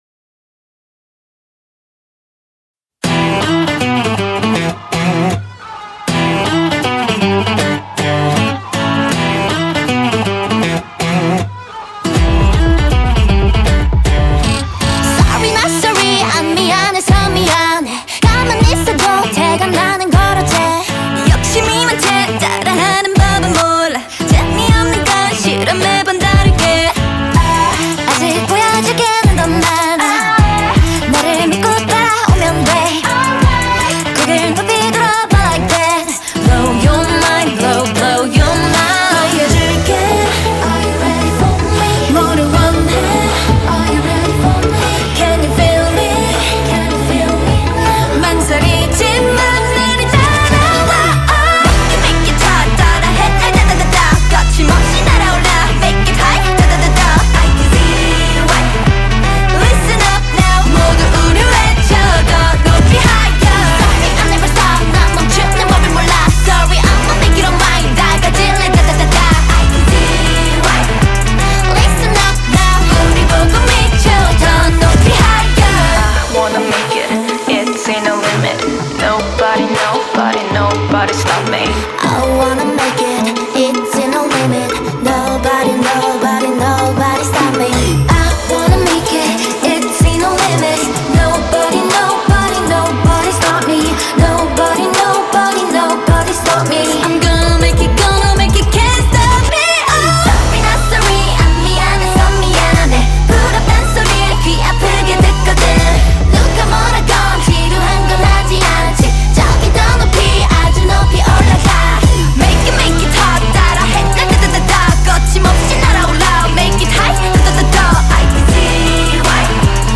BPM79
MP3 QualityMusic Cut